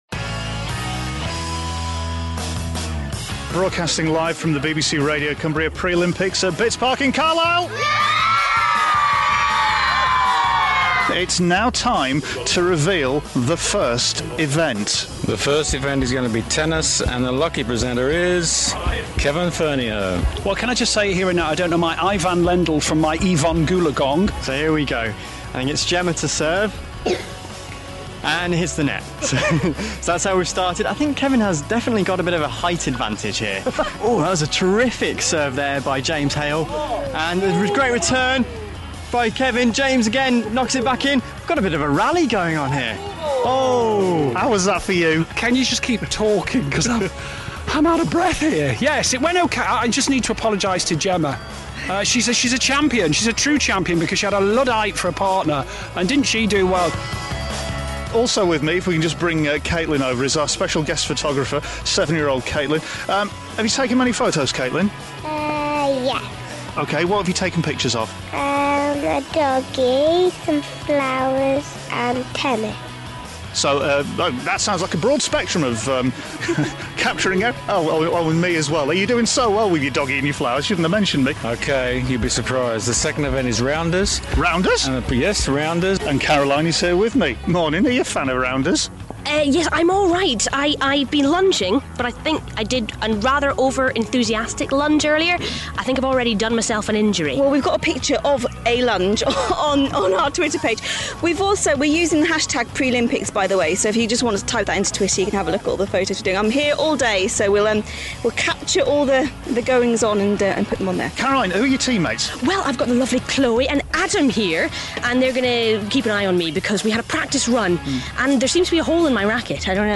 Montage of the Prelympics, which was a large sporting event attended by local youths and the presentation team at BBC Radio Cumbria in the summer of 2012.